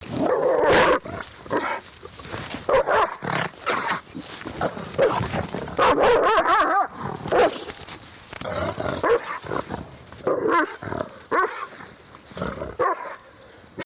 Tiếng sợi Xích kêu và tiếng Chó Gầm gừ cắn xé, sủa, tức giận…
Thể loại: Tiếng vật nuôi
Description: Tiếng sợi xích va đập leng keng, loảng xoảng, hòa lẫn tiếng chó gầm gừ, rít lên, sủa vang lên dữ dội. Âm thanh chói tai, dồn dập, như tiếng kim loại nghiến vào nhau, tiếng răng nanh va chạm, tiếng thở hổn hển, giận dữ. Mỗi tiếng sủa vang vọng, tiếng gừ trầm đục, tiếng xích kéo căng, tạo nên cảm giác căng thẳng, đe dọa, cuồng nộ ...
tieng-soi-xich-keu-va-tieng-cho-gam-gu-can-xe-sua-tuc-gian-www_tiengdong_com.mp3